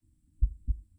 Creatures » Growling Monster 003
描述：A growling monster sound effect created using my voice and extensive pitch shifting in Audacity. Can be used for monsters, dragons and demons.
标签： Creature Animal Dragon Demon Monster Growl Growling
声道立体声